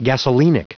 Prononciation du mot gasolinic en anglais (fichier audio)